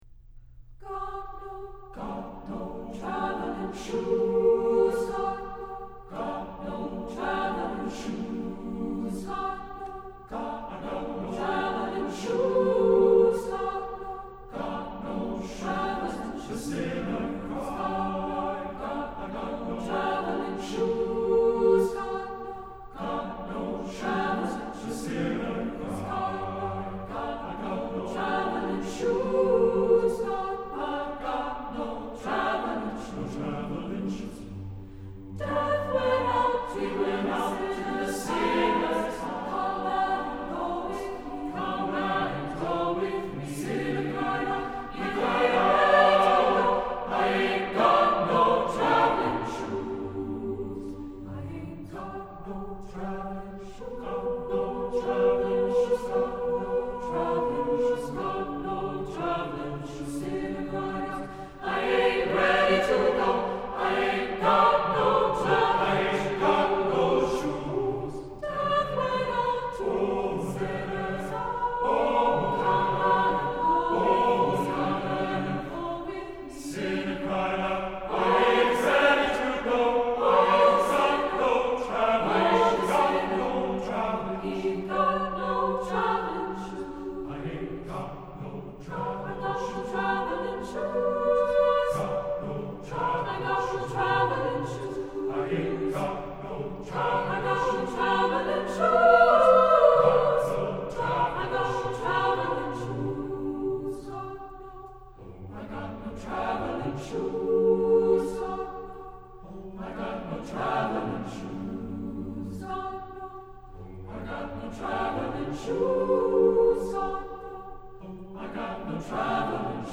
Composer: Spirituals
Voicing: SATB a cappella